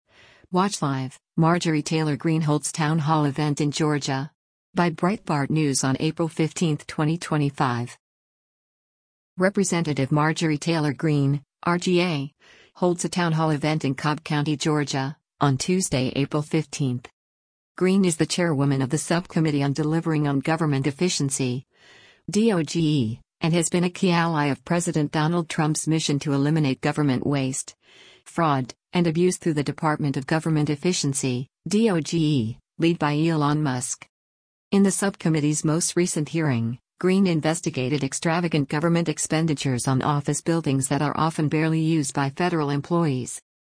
Rep. Marjorie Taylor Greene (R-GA) holds a town hall event in Cobb County, Georgia, on Tuesday, April 15.